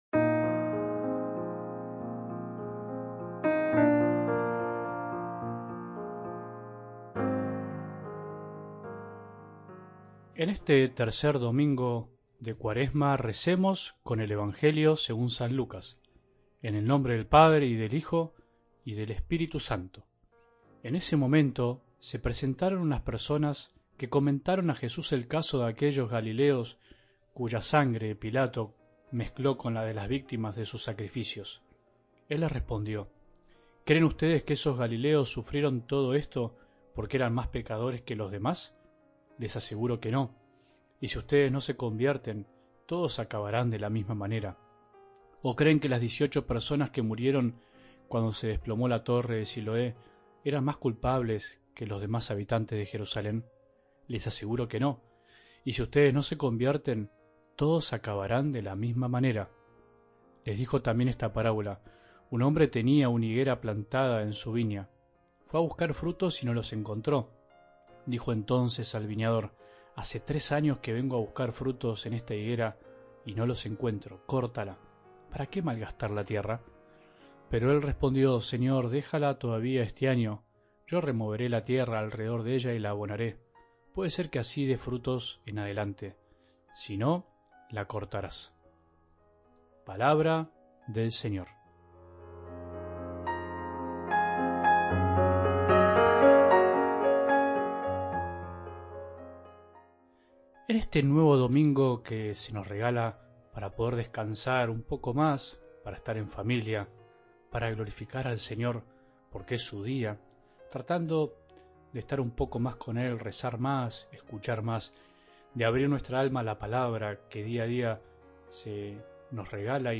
Oración 23 de Marzo